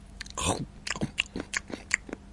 Chicle
描述：口香糖的声音
Tag: 口香糖 咀嚼 进食